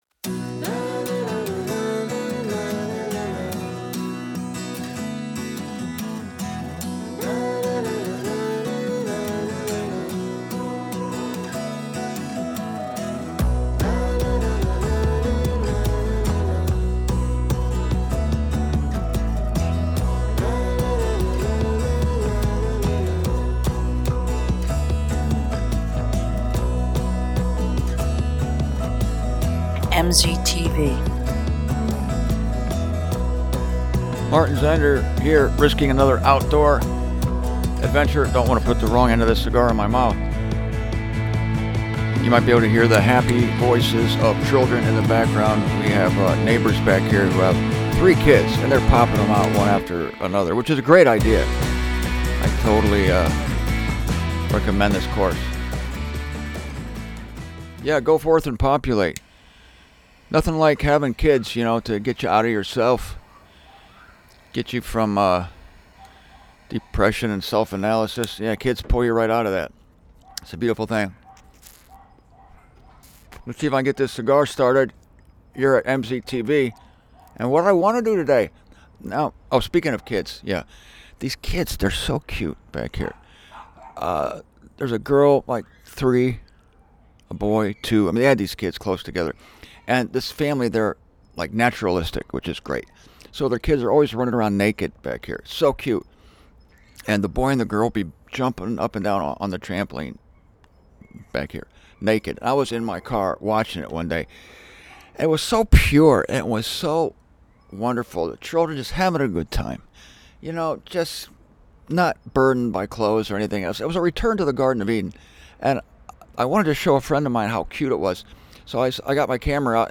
Here is yet another teaching on the absolute and relative perspectives.